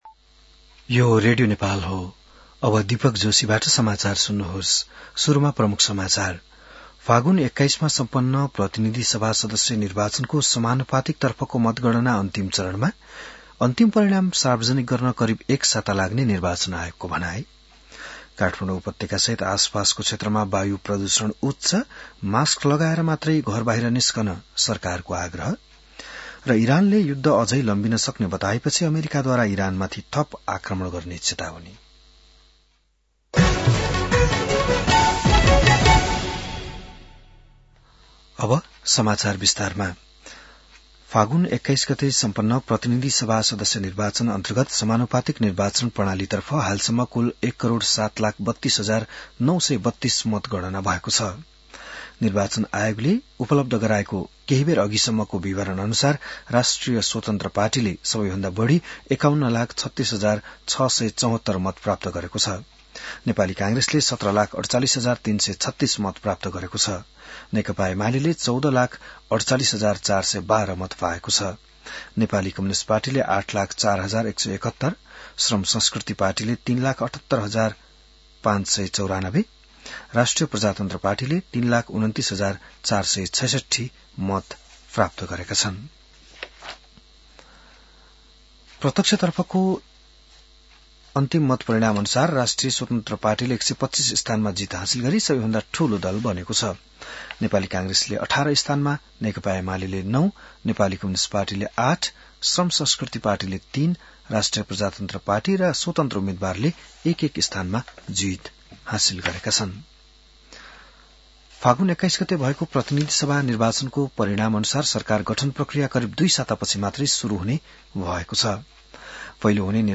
बिहान ९ बजेको नेपाली समाचार : २७ फागुन , २०८२